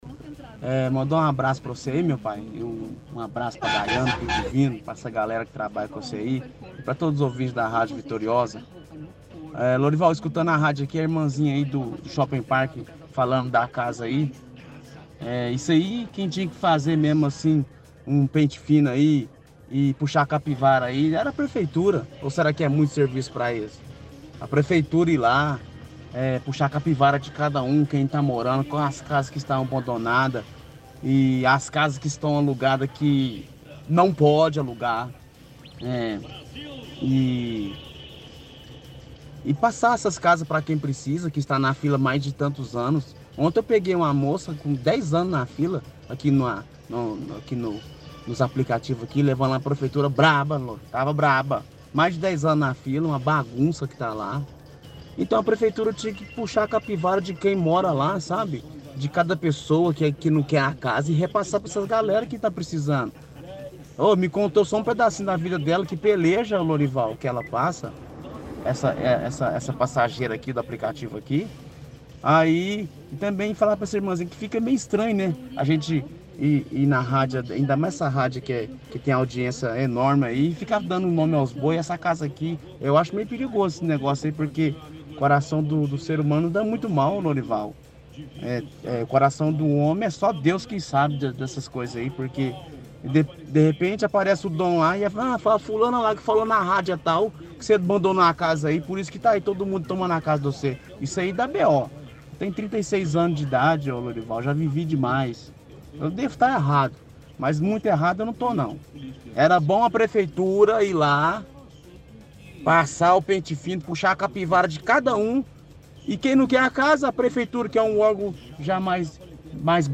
– Outro ouvinte afirma que quem tinha que fiscalizar essa questão da moradia é a prefeitura.